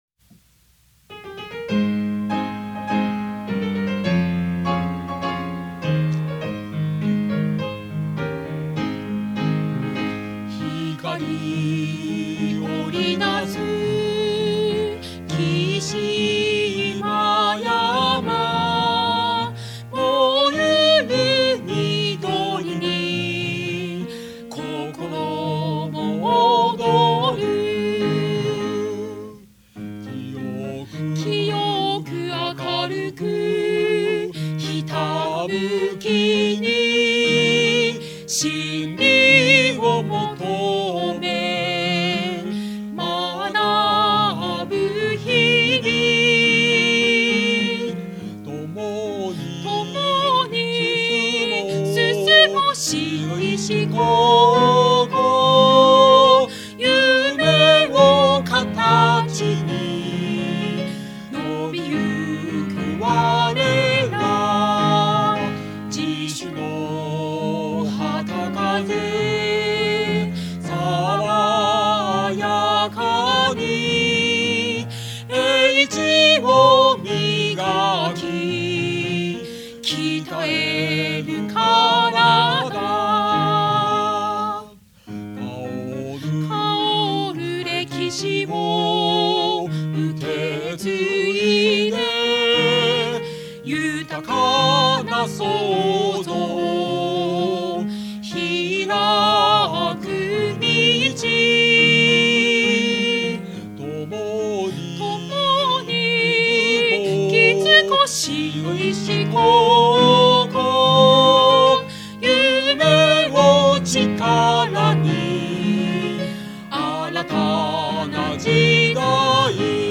校訓／校歌／校章／校則／グランドデザイン／スクール・ポリシー 〇校 訓 「清明 自律 創造」 〇校 歌 佐賀県立白石高等学校校歌 平成30年5月制定 校歌(MP3) 〇校 章・校 旗 〇生徒心得 ↓ ↓ ↓ ここをクリックしてください。